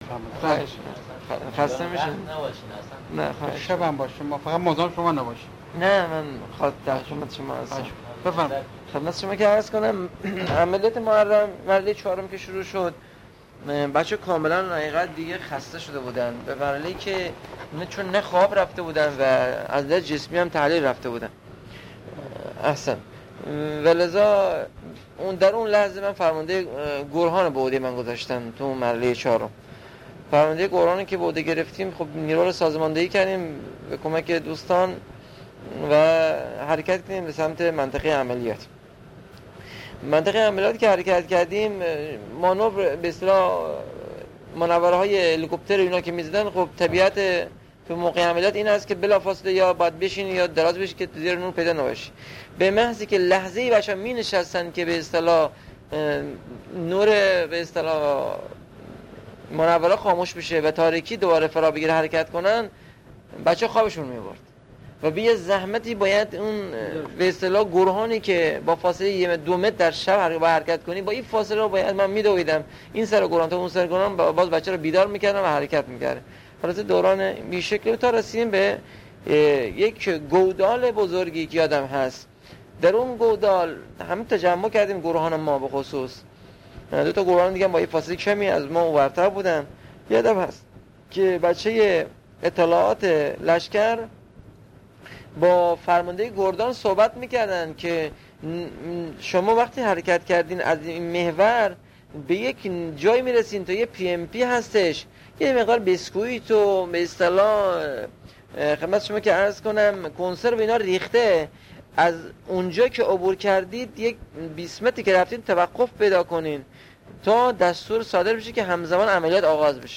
صوت / مصاحبه